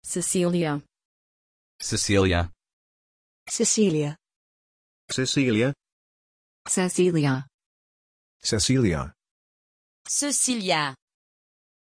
Aussprache von Cecília
pronunciation-cecília-en.mp3